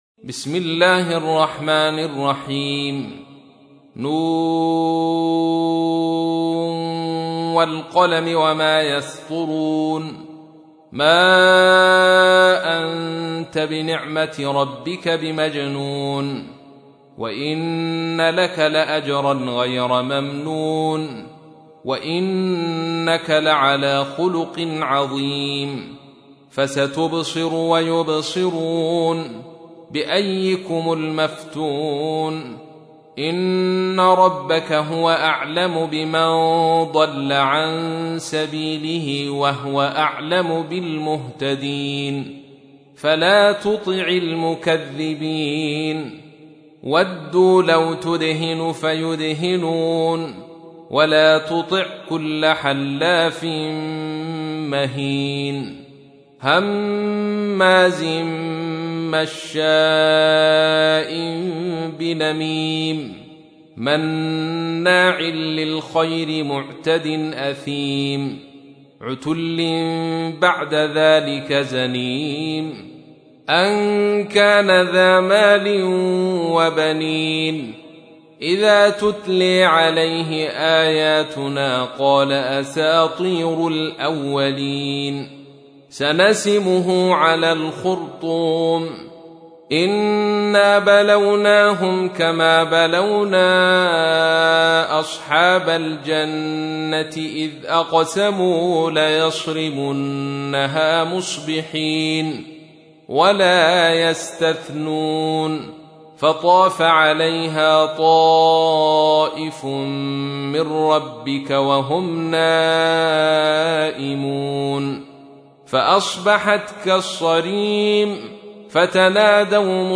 تحميل : 68. سورة القلم / القارئ عبد الرشيد صوفي / القرآن الكريم / موقع يا حسين